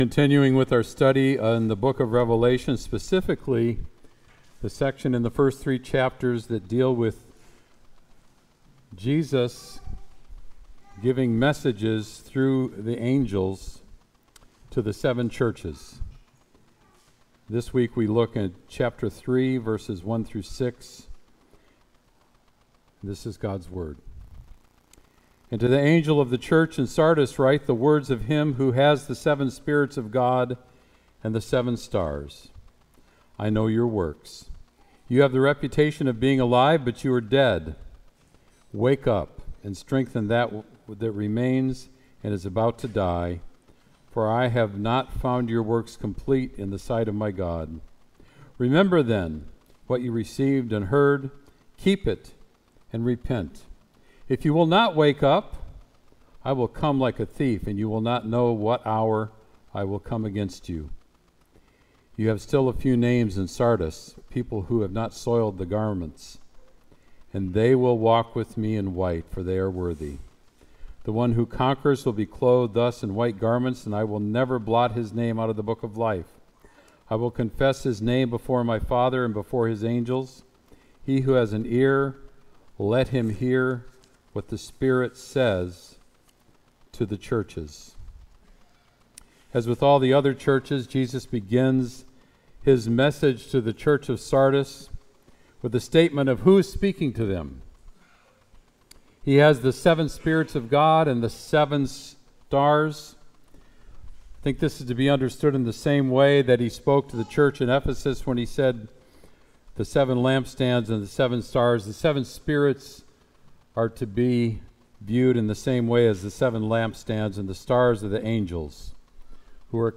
Sermon “Dead or Alive”